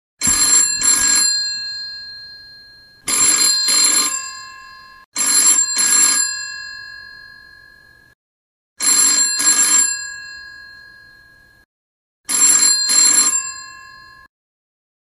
Android, Klassisk, Klassisk Telefon